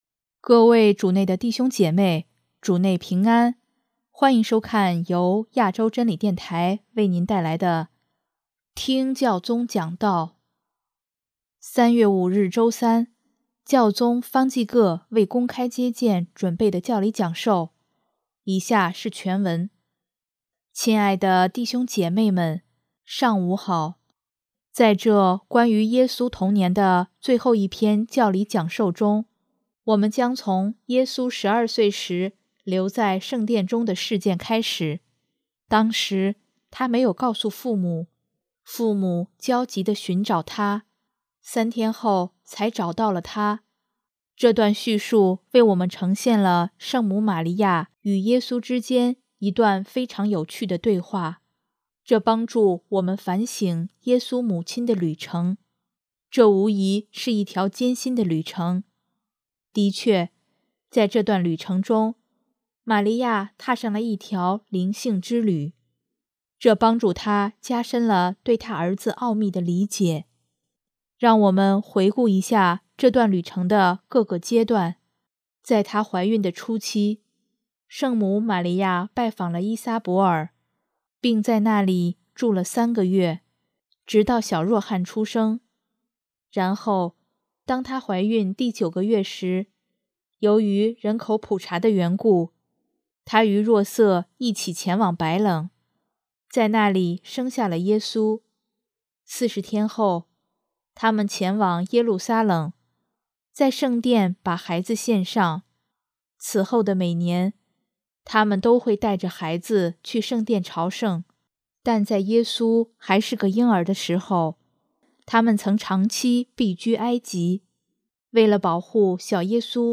3月5日周三，教宗方济各为公开接见准备的教理讲授，以下是全文：